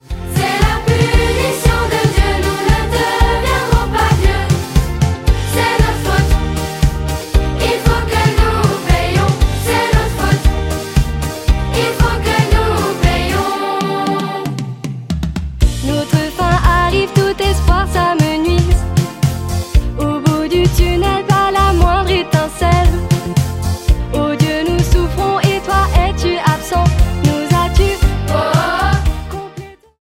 Album musical